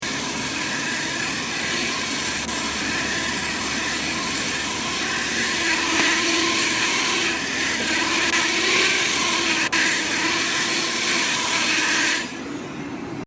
grinding – Hofstra Drama 20 – Sound for the Theatre
Field Recording #7 – Cassette Player
This is the sound the cassette player in my car makes after two hours; it’s an output source to play music from my iPhone, and I guess my cassette player really doesn’t like working this hard on a drive home from New Hampshire…